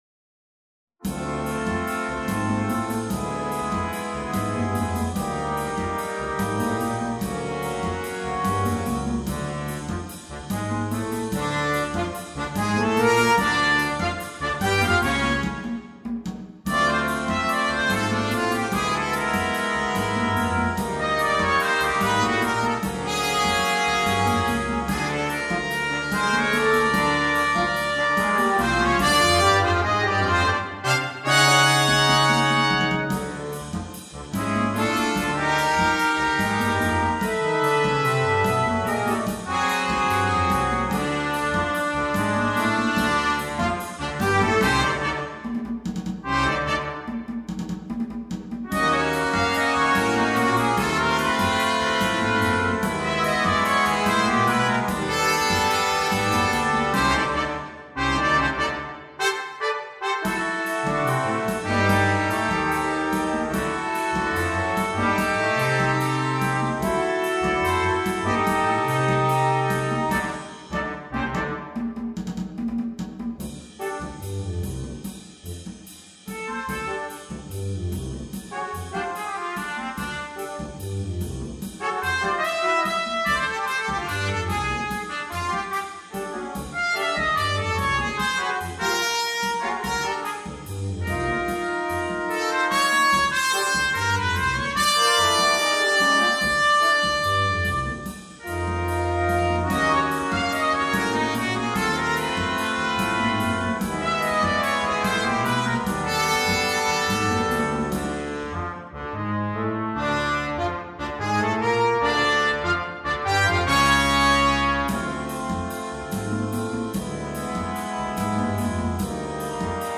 Brass Quintet (optional Drum Set)